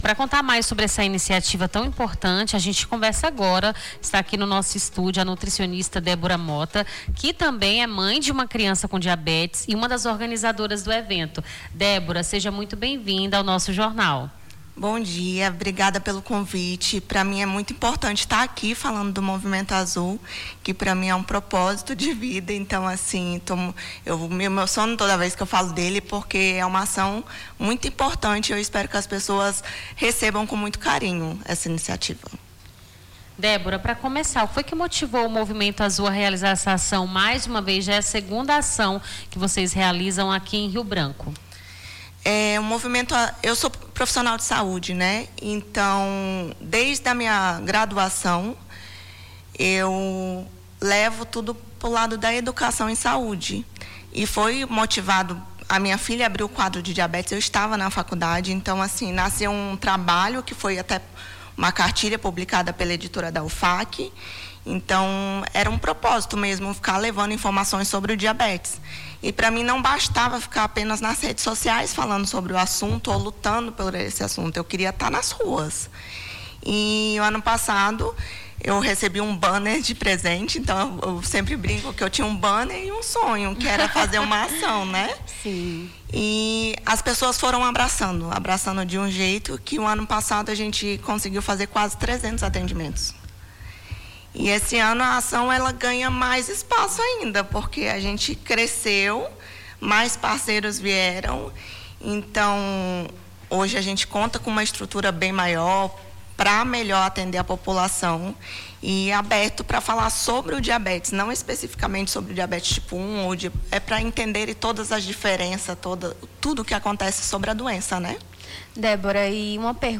Nome do Artista - CENSURA - ENTREVISTA (ACAO FAMILIA AZUL) 28-11-25.mp3